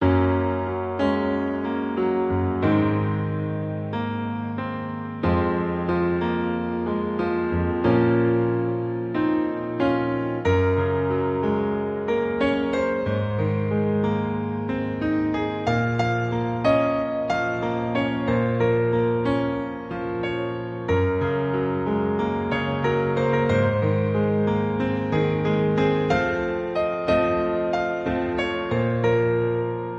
• Key: F# Major
• Instruments: Piano solo
• Genre: TV/Film